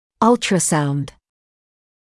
[‘ʌltrəsaund][‘алтрэсаунд]ультразвук